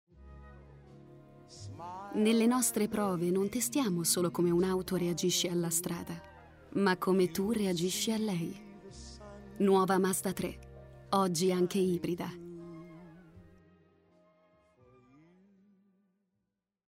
Commercial, Deep, Young, Natural, Distinctive
Explainer
Her voice is basically young, deep and calm, but also energetic, gritty, characterful.